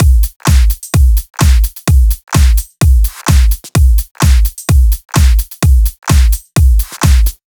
VDE1 128BPM Full Effect Drums 1.wav